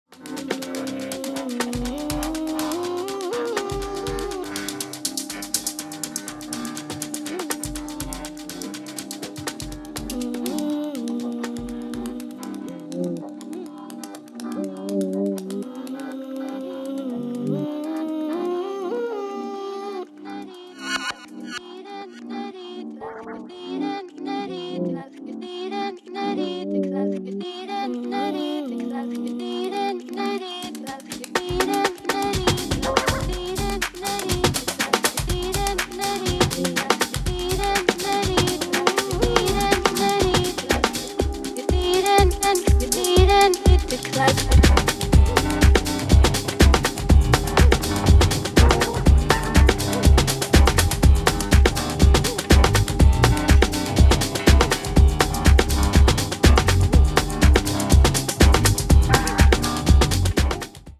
浮遊感や中毒性、温かみを備えたナイスな一枚に仕上がっています。